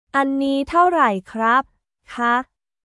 アンニー タオライ クラップ／カー